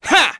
Neraxis-Vox_Attack2_kr.wav